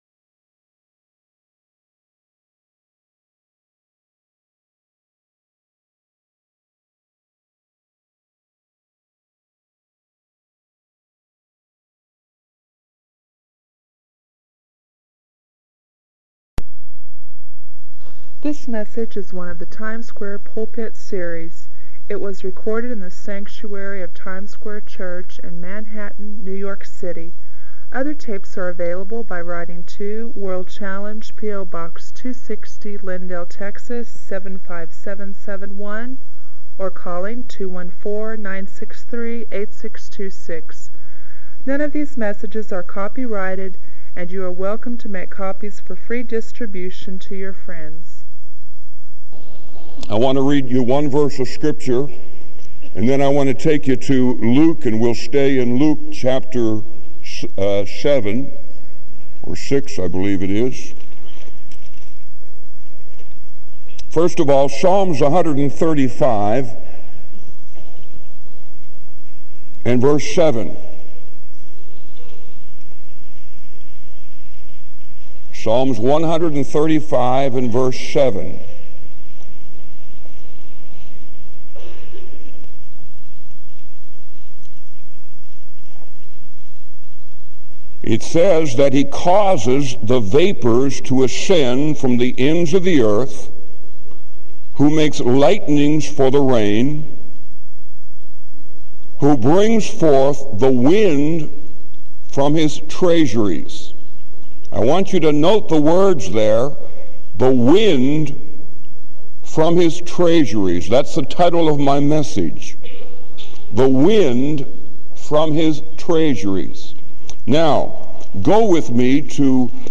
SermonIndex